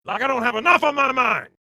mine_revpitch.mp3